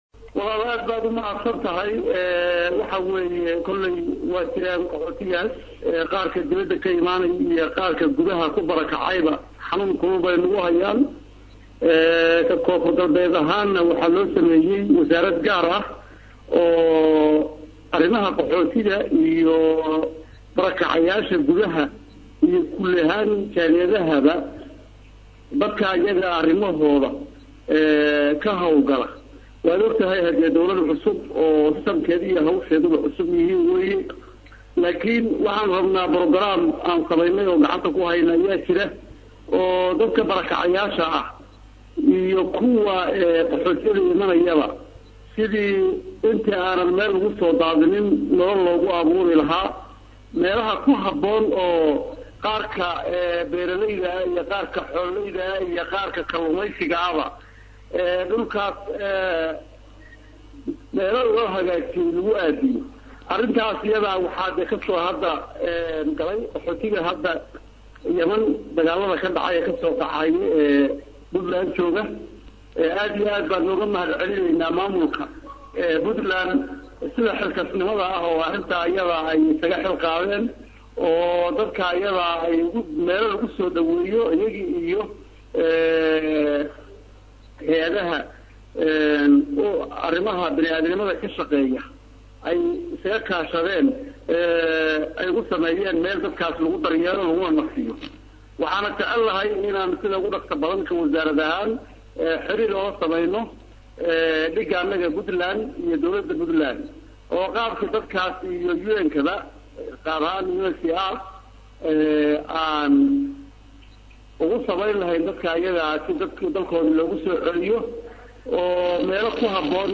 Halkan Ka Dhageys CodkaWasiirka Dib U Dajinta KGS